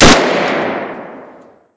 assets/psp/nzportable/nzp/sounds/weapons/m1garand/shoot.wav at 29b8c66784c22f3ae8770e1e7e6b83291cf27485